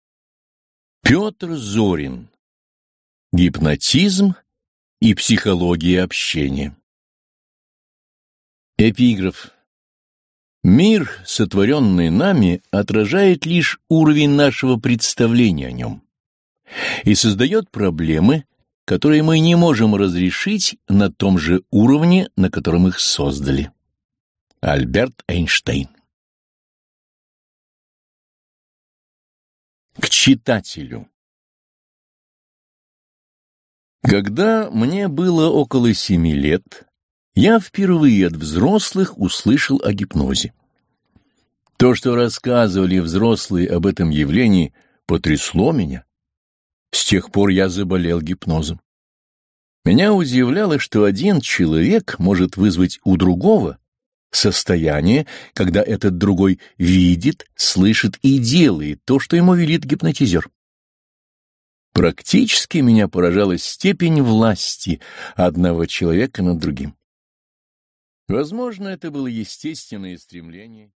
Аудиокнига Гипнотизм и психология общения | Библиотека аудиокниг